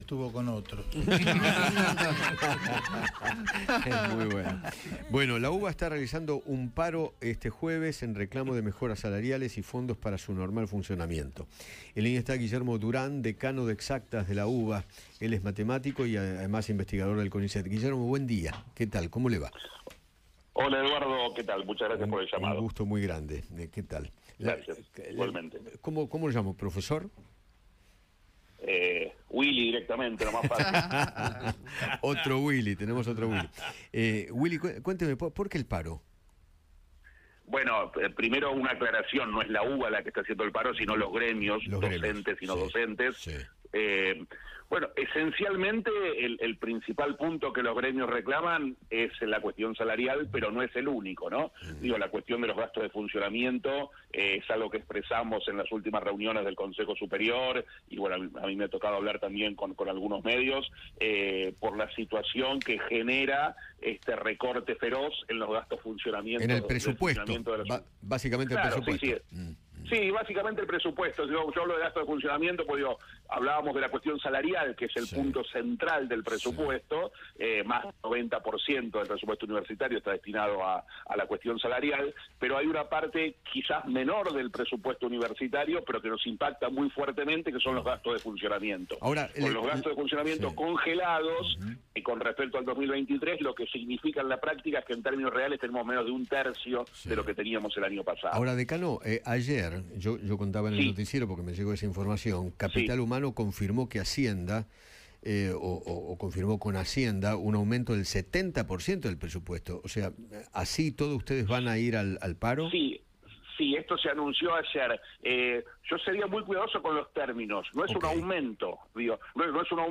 dialogó con Eduardo Feinmann sobre el paro de las universidades nacionales en reclamo de mejoras salariales y del financiamiento.